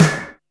BLAST.wav